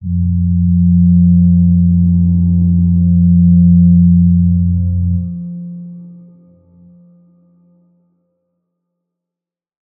G_Crystal-F3-f.wav